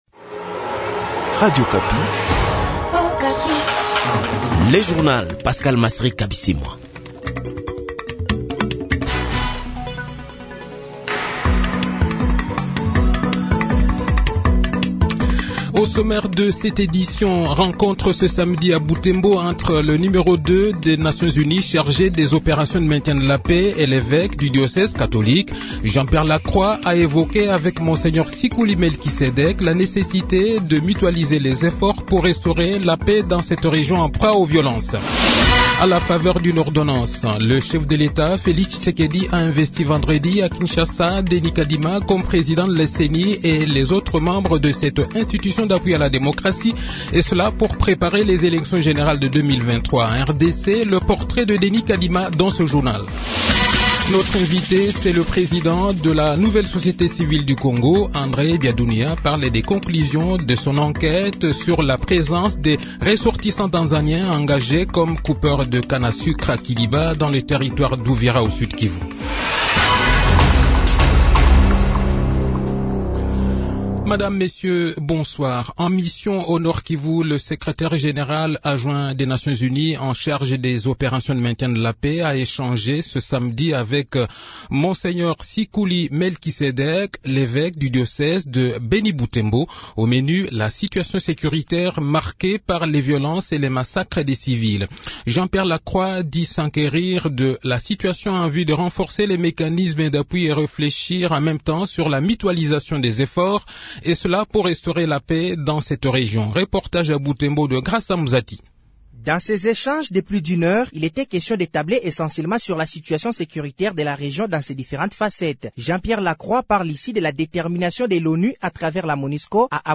Le journal de 18 h, 23 Octobre 2021